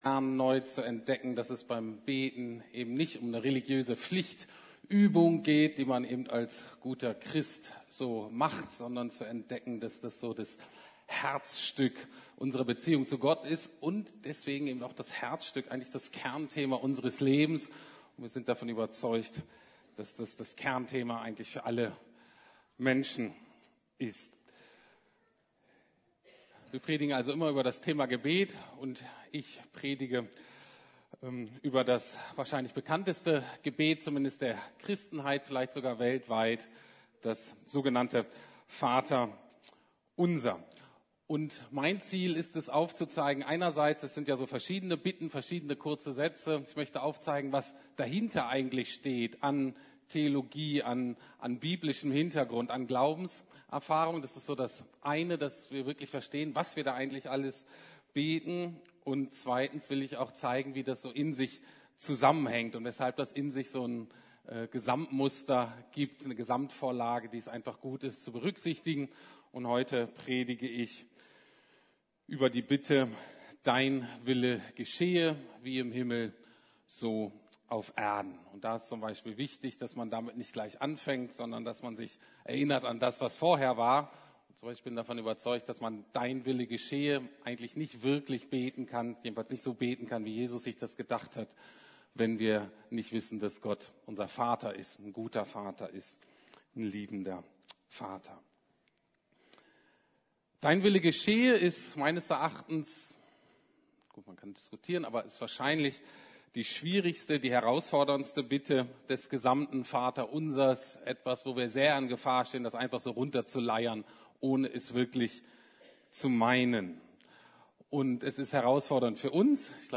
Dein Wille geschehe! ~ Predigten der LUKAS GEMEINDE Podcast